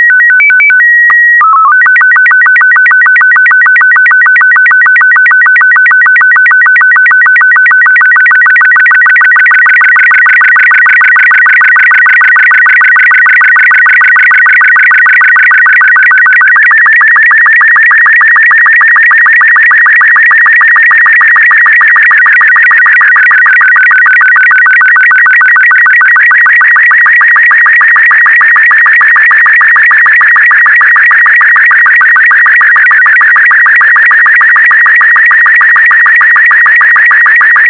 The audio sounds pretty bizarre, parts of it almost sound like connecting to dial-up internet.
The example should remind you of the original .wav file for this challenge.